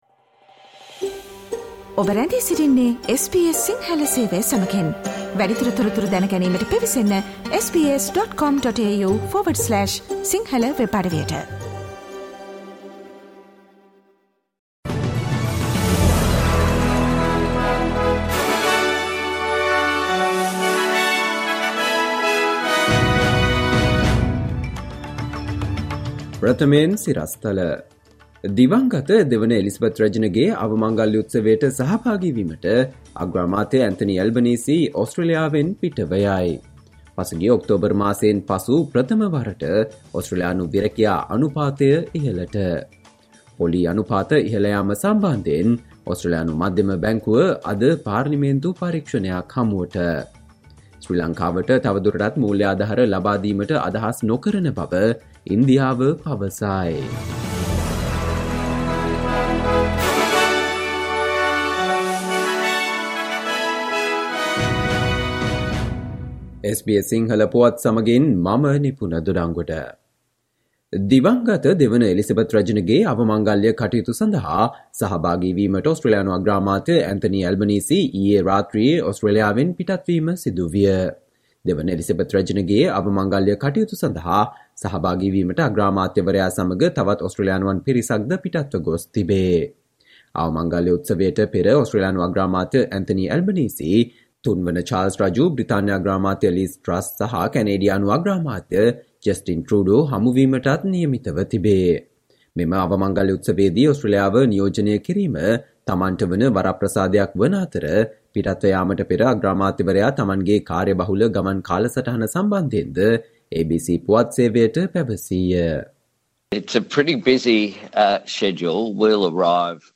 Listen to the SBS Sinhala Radio news bulletin on Friday 16 September 2022